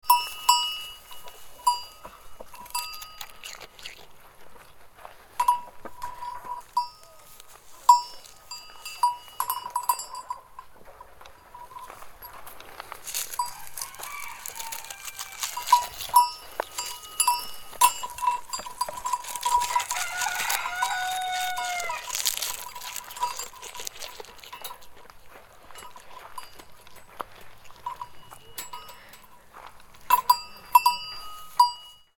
Goat Bell Sound Effect
Goat grazing and chewing grass on a farm, with a bell jingling around its neck. Recorded on a domestic animal farm. Farm animal sounds.
Goat-bell-sound-effect.mp3